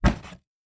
Punching Box Intense G.wav